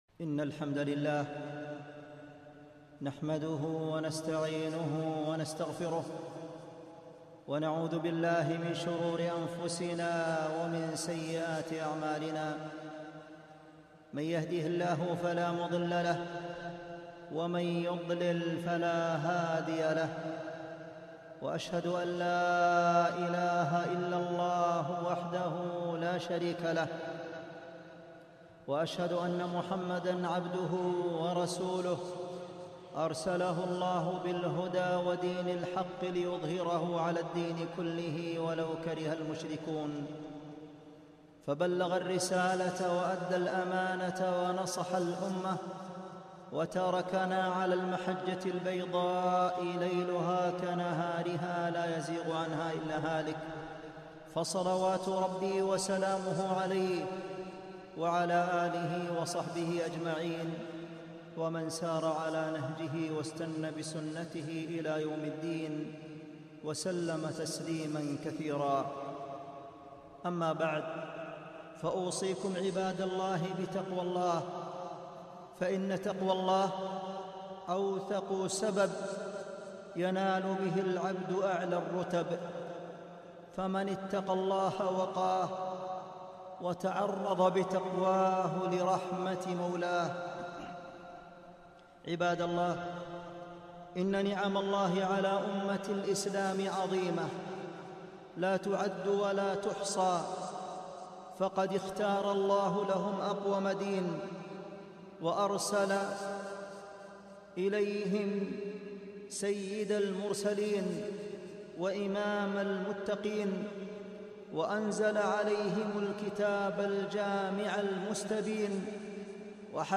خطبة - فضائل الصحابة